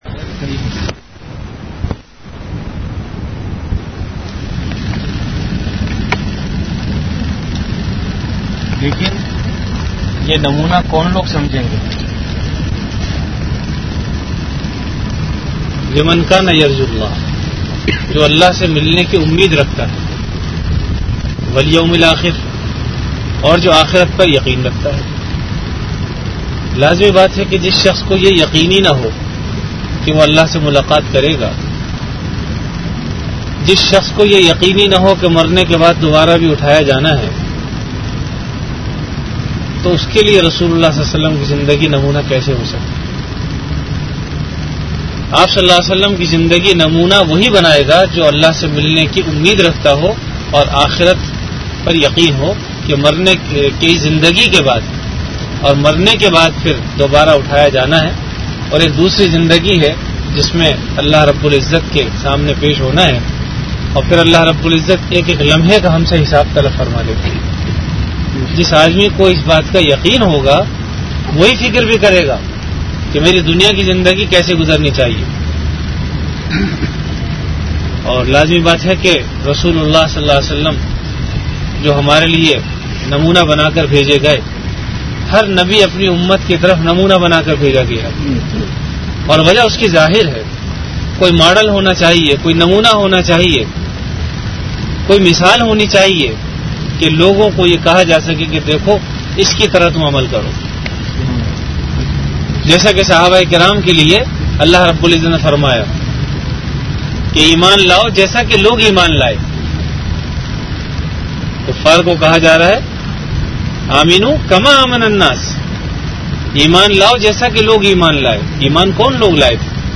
Bayanat · Jamia Masjid Bait-ul-Mukkaram, Karachi
Venue Jamia Masjid Bait-ul-Mukkaram, Karachi Event / Time After Fajr Prayer